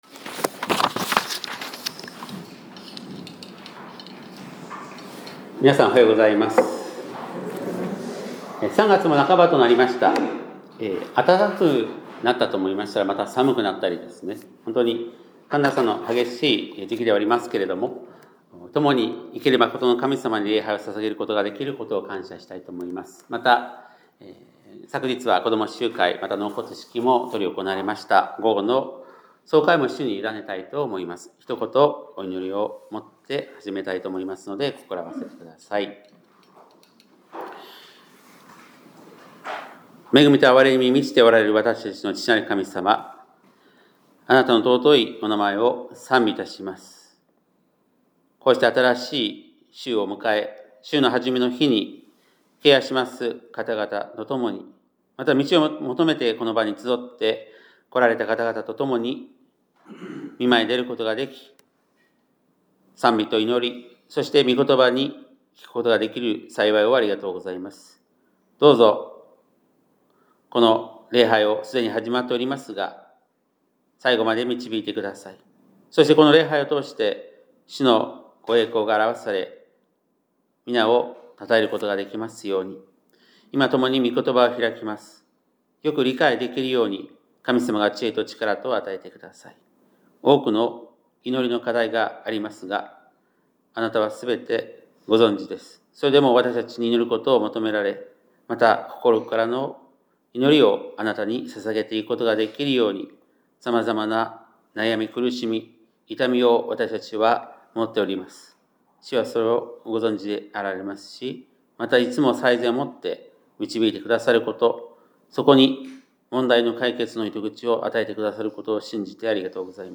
2026年3月15日（日）礼拝メッセージ